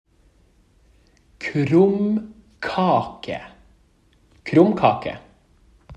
Learn how to pronounce “krumkake” in Norwegian
The rough way of pronouncing the Norwegian word “krumkake” sounds like “kroom-kah-keh.”
1. “kroom” – The “k” is pronounced as a hard “k” sound, like the “k” in “key.” The “r” is rolled or trilled, which is pretty common in Norwegian. The “oo” is like the “oo” in “food,” and the “m” is pronounced the same as in English.
2. “kah” – The “k” is again a hard “k” sound, the “a” is pronounced like the “a” in “father,” and the “h” is silent.
3. “keh” – The “k” is a hard “k” sound, and the “eh” is like the “e” in “bed.”